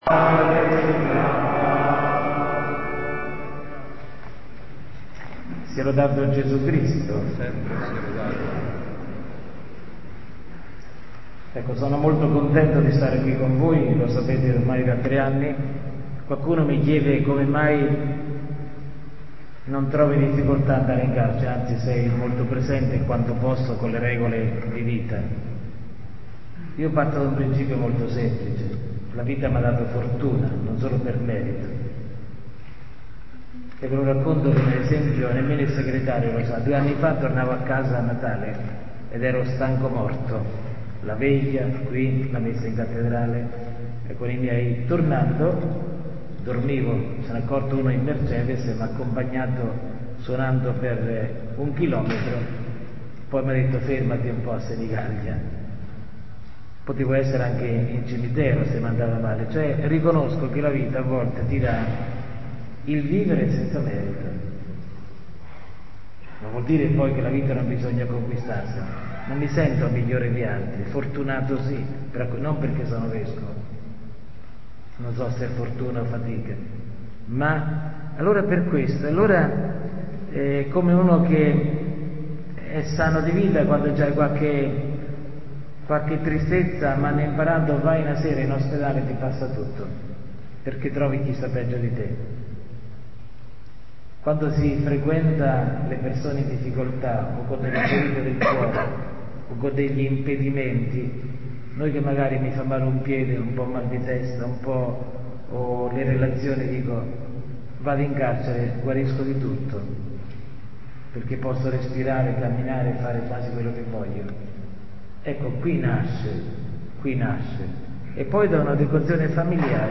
Carcere-di-Fossombrone-con-la-Madonna-di-Loreto.mp3